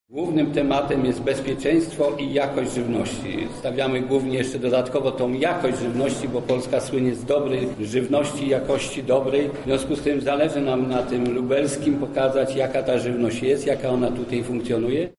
-mówi senator Jerzy Chróścikowski, przewodniczący Komisji Rolnictwa i Rozwoju Wsi.